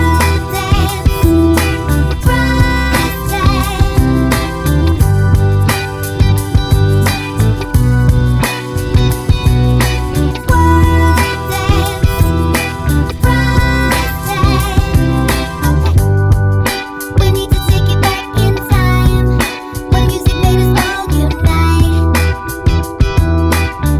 No Rap Two Semitones Up Pop (2010s) 3:42 Buy £1.50